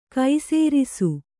♪ kai sērisu